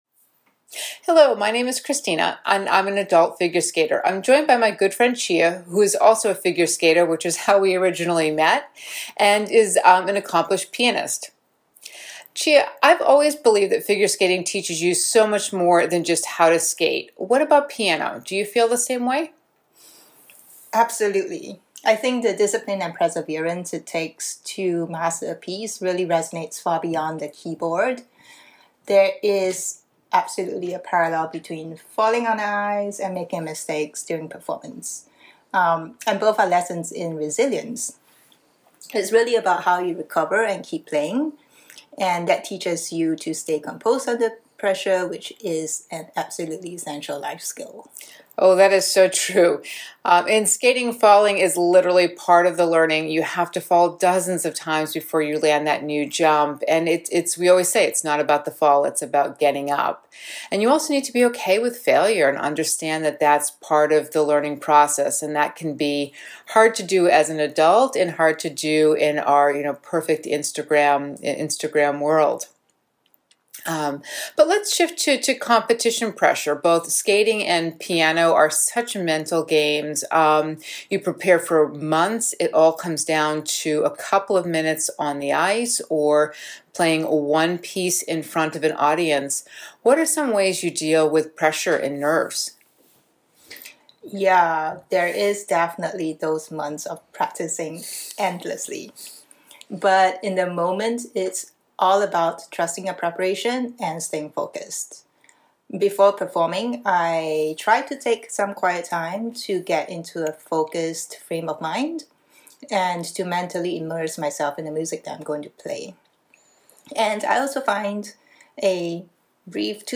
They uncover thoughtful parallels between falling on the ice and making mistakes in music, emphasizing that resilience and recovery are at the heart of personal growth. The conversation also touches on managing the mental pressures of competitions and performances, highlighting the importance of preparation, focus, and mental toughness.